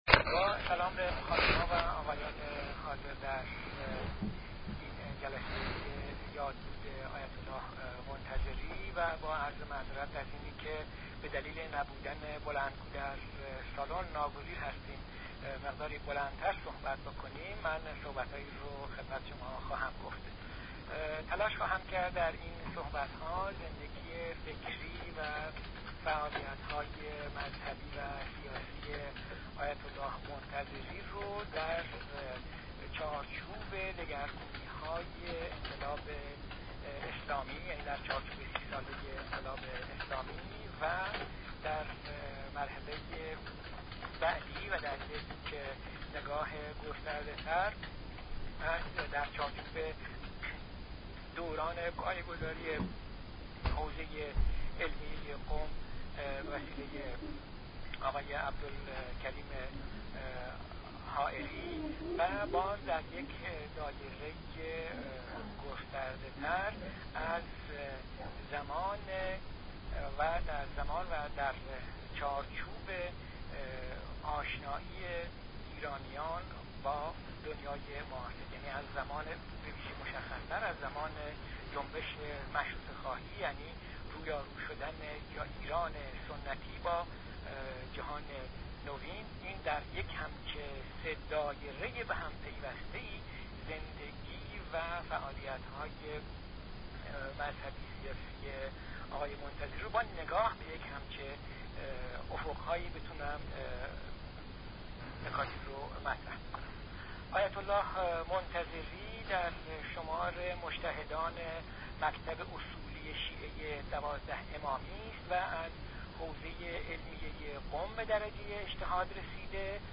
در سخنرانی زیر به زندگی فکری، فعالیت های مذهبی و سیاسی آیت الله منتظری در چهارچوب انقلاب اسلامی و در نگاهی گسترده تر، در دوران پایه گذاری حوزه علمیه قم، و باز هم در یک دایره ای وسیع تر در چارچوب آشنائی ایرانیان با دنیای معاصر و مشخص تر زمان مشروطه خواهی، یعنی رویارو شدن ایران سنتی با جهان نوین ... پرداخته شده است. فایل صوتی ضمیمه، سخنرانی